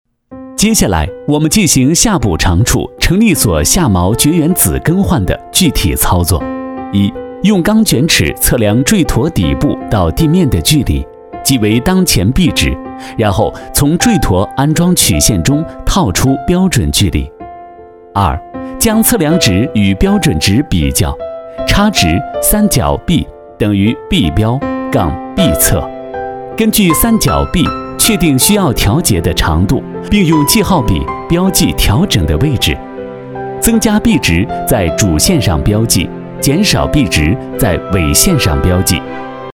ppt男322号（知情权）
轻松自然 ppt幻灯片 描述： 下载 现在咨询 课件男246号（医学讲解）